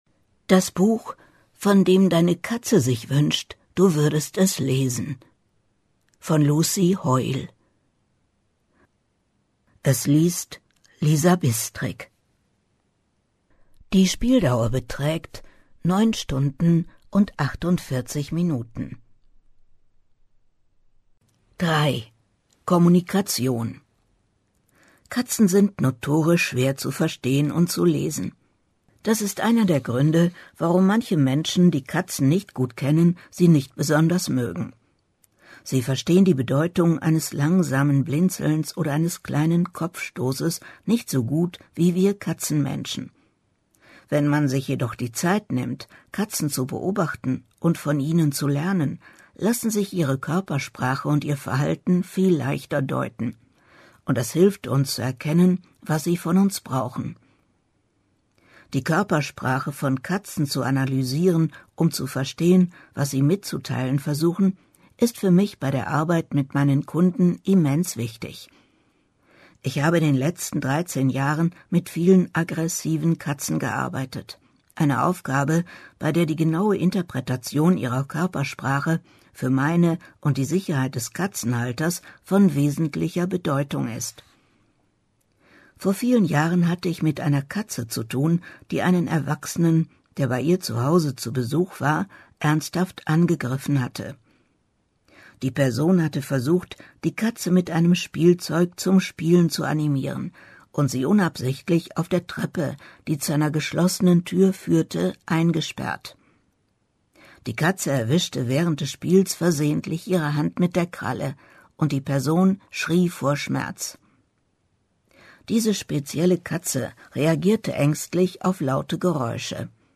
Buchtipps - 03. Neues im März